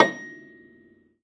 53v-pno15-A4.aif